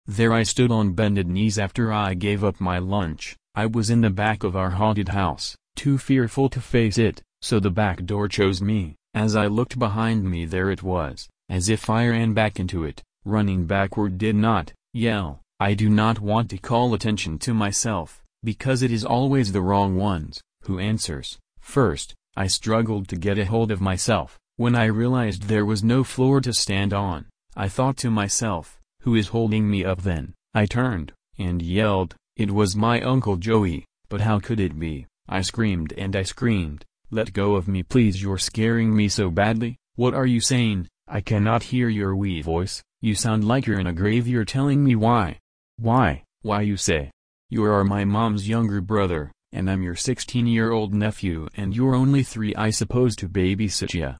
love the reading and the music was enjoyable and congratulations on your win in the contest!